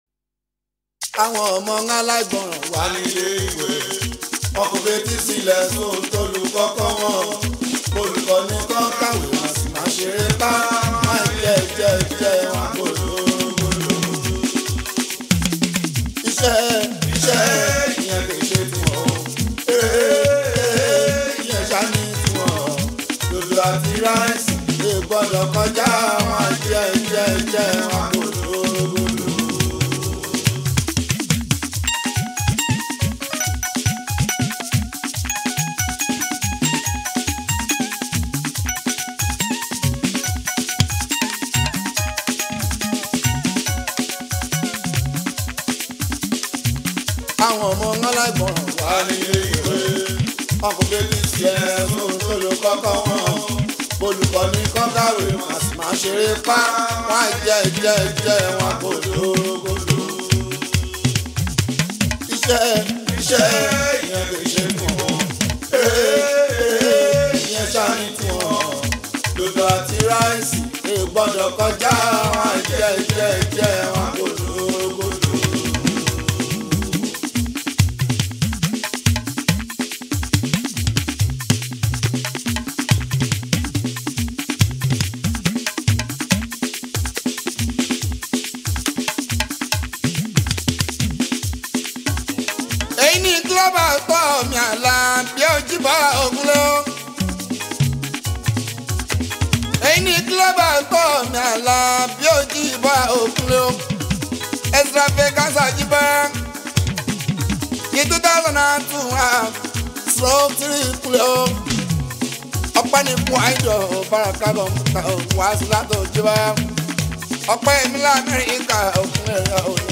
Fuji, Highlife
Nigerian Yoruba Fuji track
especially if you’re a lover of Yoruba Fuji Sounds